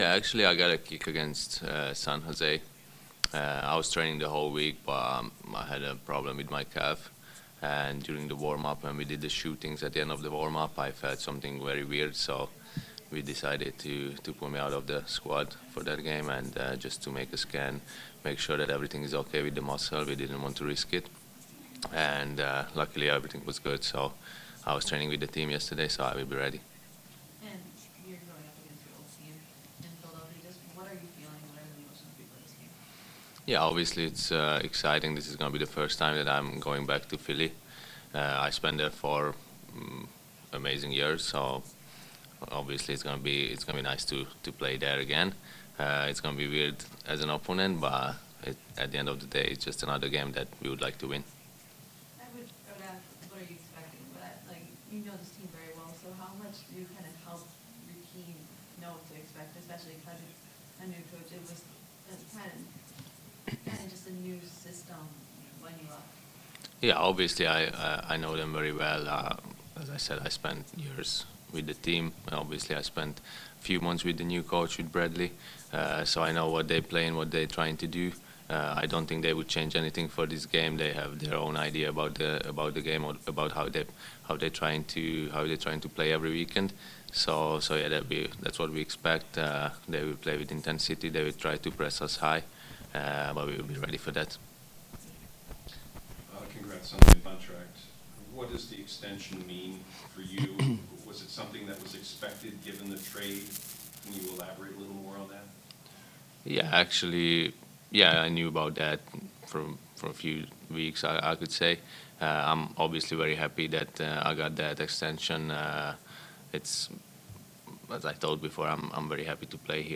Daniel Gazdag met with media on Thursday about returning to Philadelphia for a match (courtesy Crew Communications)